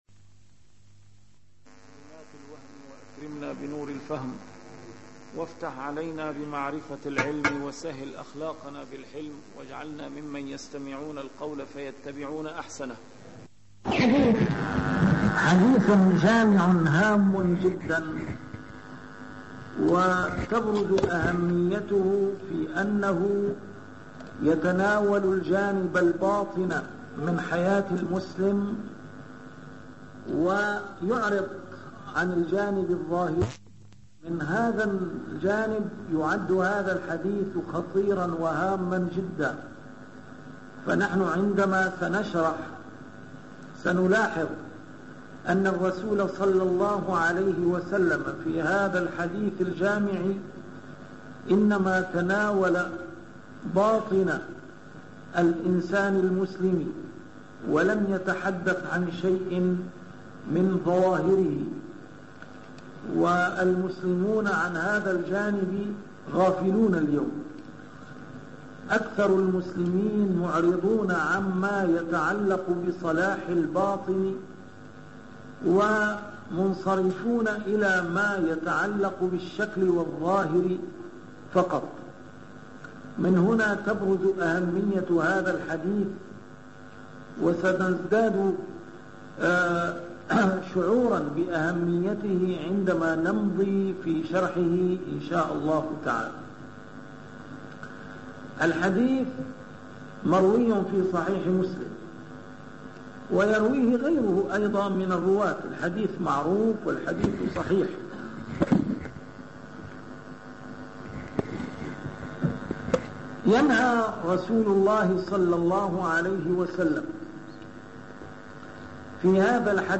A MARTYR SCHOLAR: IMAM MUHAMMAD SAEED RAMADAN AL-BOUTI - الدروس العلمية - شرح الأحاديث الأربعين النووية - بداية شرح الحديث الخامس والثلاثون: حديث أبي هريرة (لا تحاسدوا ولا تناجشوا …) 113